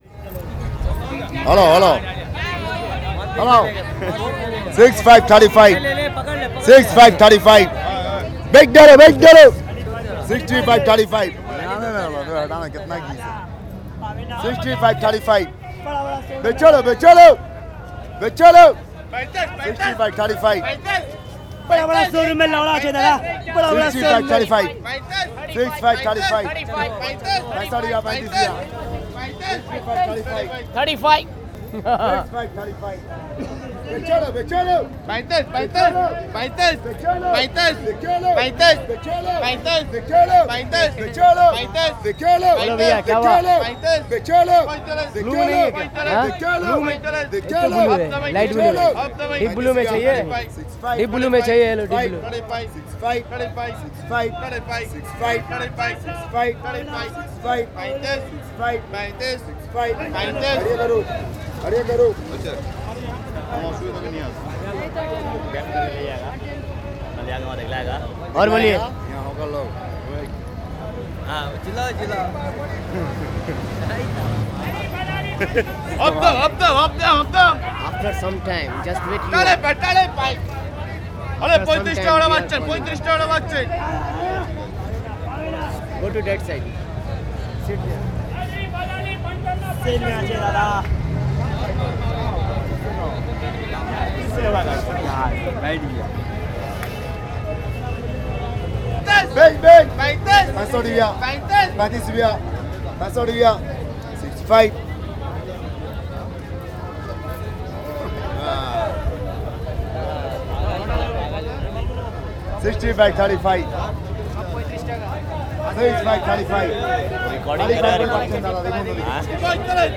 Chowringhee路上的小贩，录于1997年6月
MP3 文件 描述 Hawkers 此内容发布于 2020年05月08日 - 11:40 其他1种语言 English en Hawkers on Chowringhee Road, recorded in June 1997 原版 更多阅览 Hawkers on Chowringhee Road, recorded in June 1997 阅读最长 讨论最多 您可以在这里找到读者与我们记者团队 正在讨论 交流的话题。